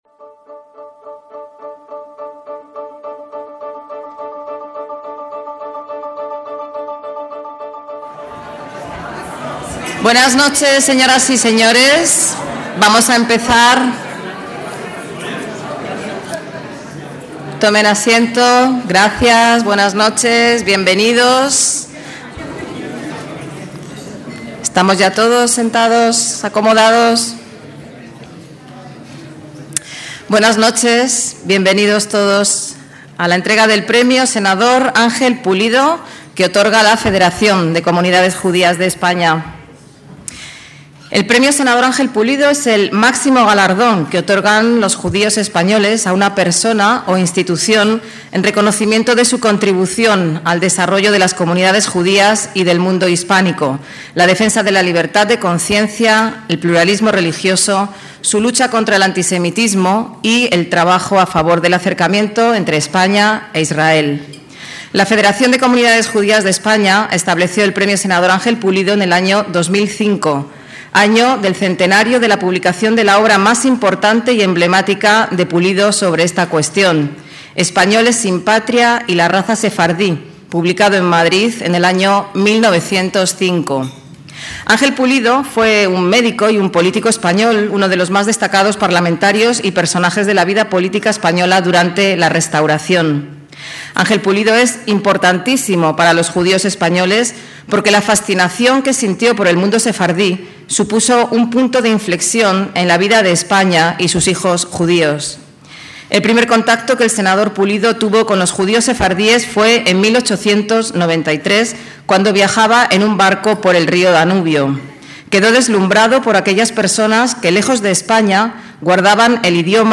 ACTOS EN DIRECTO - El 7 de julio de 2022 tuvo lugar en el Hotel Intercontinental de Madrid el acto de entrega del Premio Senador Pulido 2021, con el que la Federación de Comunidades Judías de España (FCJE) galardona a las figuras más destacadas en su labor como garantes de un mayor conocimiento de la sociedad española del hecho judío, como lo ha sido durante años Miguel de Lucas como Director General (2013 - 2022) del Centro Sefarad Israel y Jefe de la Delegación Española ante la Alianza Internacional de Recuerdo del Holocausto (IHRA), y hoy Embajador de España en el Reino de Jordania.